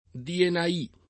vai all'elenco alfabetico delle voci ingrandisci il carattere 100% rimpicciolisci il carattere stampa invia tramite posta elettronica codividi su Facebook dienaì [ diena &+ ] escl. — voce medievale per «Dio ci aiuti»